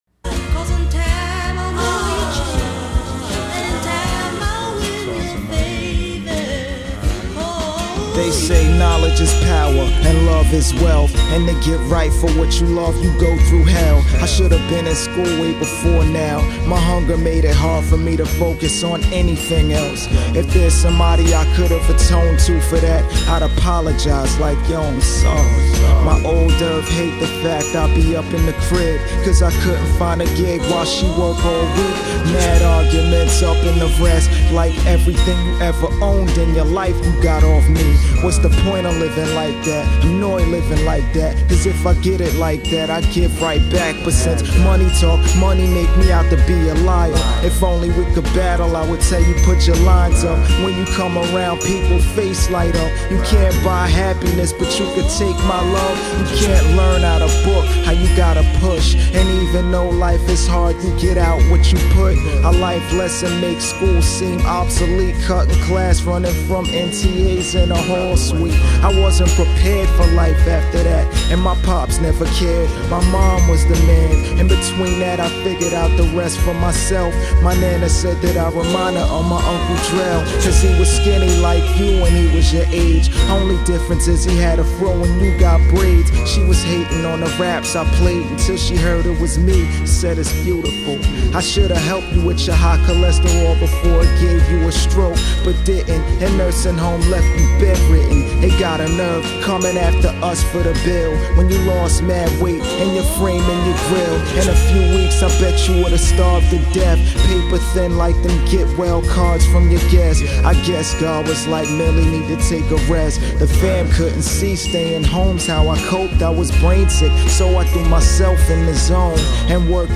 Wisdom flow over that soul loop, tight.